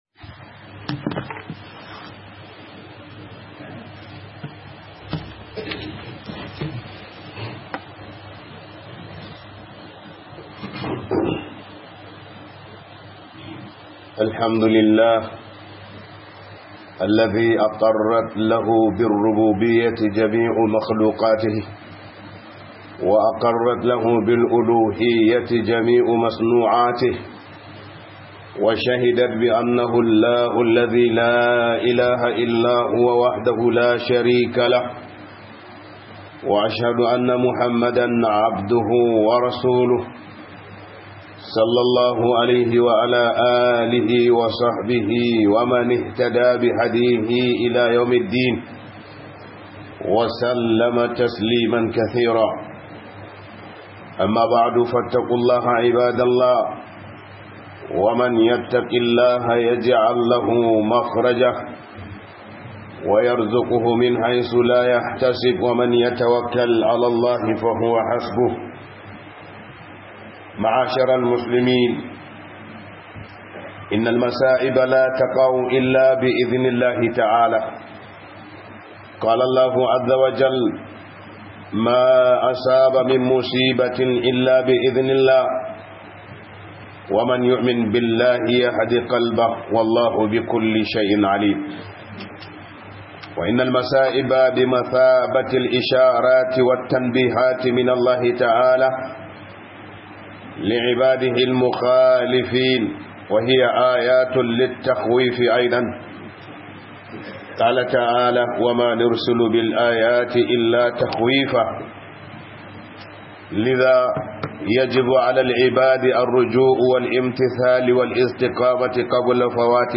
SAKON MATASA - HUDUBA